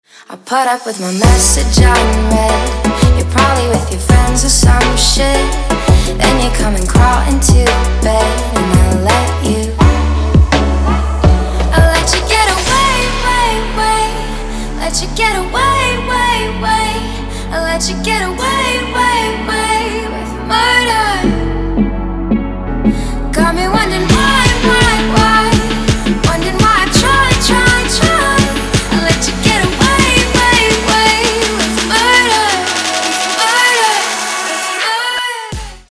• Качество: 224, Stereo
поп
женский вокал
dance
EDM
vocal